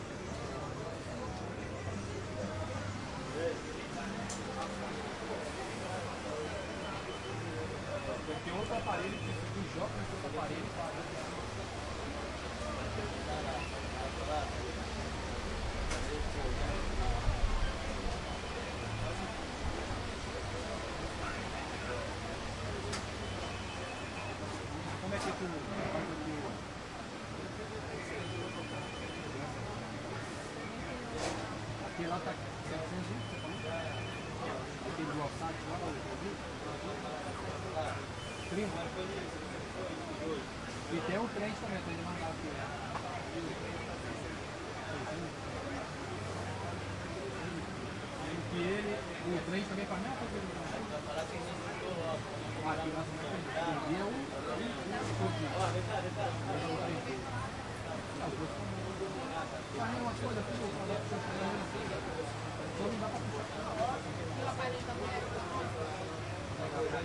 描述：Ambi来自公共市场Uruguiana在里约热内卢，在萨拉附近。